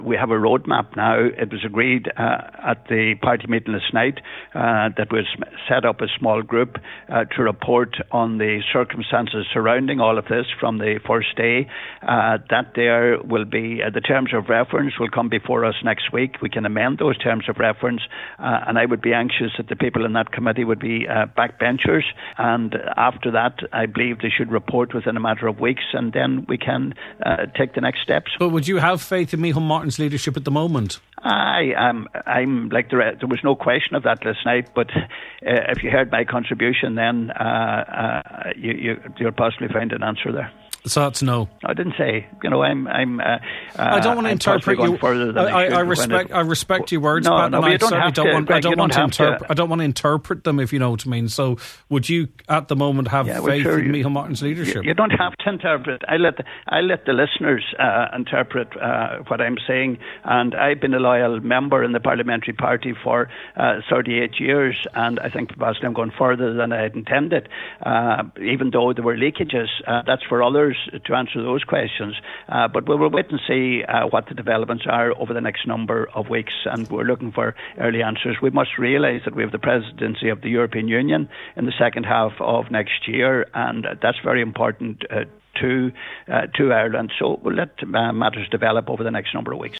On today’s Nine til Noon Show, Deputy Gallagher said a group is to be established within the party to review all aspects of nomination, and it should report back shortly…….